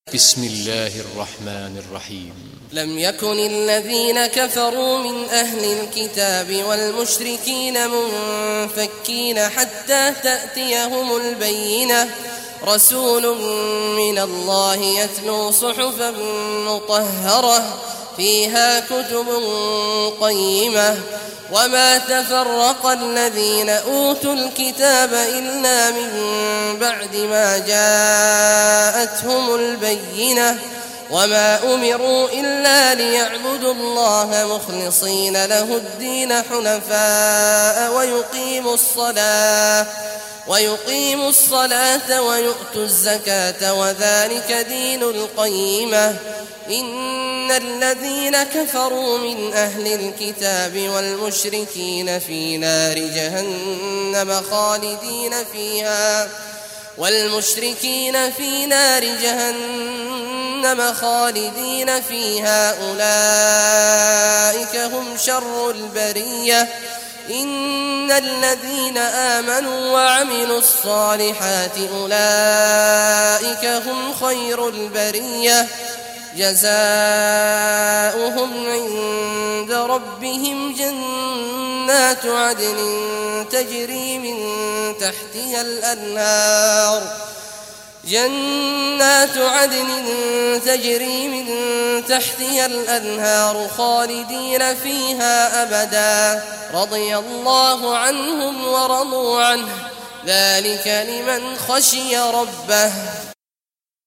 Surah Al-Bayyinah Recitation by Sheikh Juhany
Surah Al-Bayyinah, listen or play online mp3 tilawat / recitation in the beautiful voice of Sheikh Abdullah Awad Al Juhany.